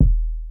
21 kick hit.wav